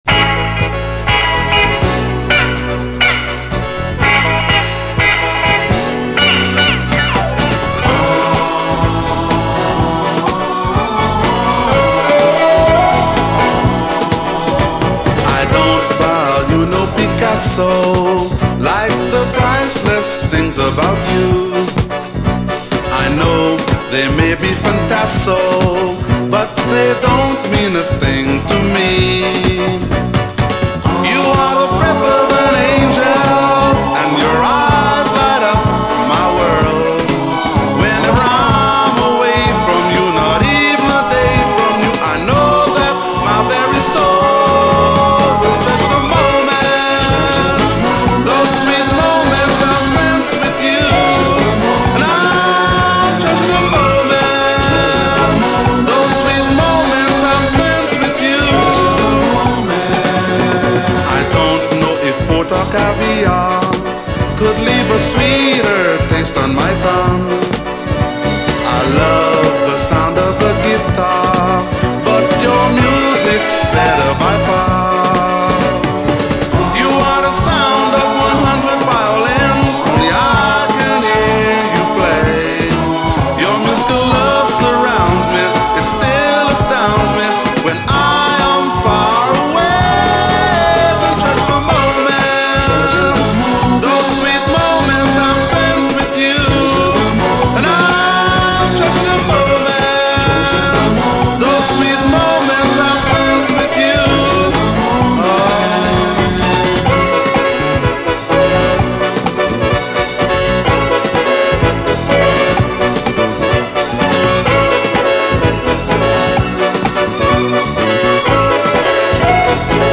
SOUL, 70's～ SOUL, DISCO
レゲエ〜カリブ由来の南国テイストがよいです！